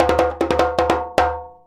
100DJEMB10.wav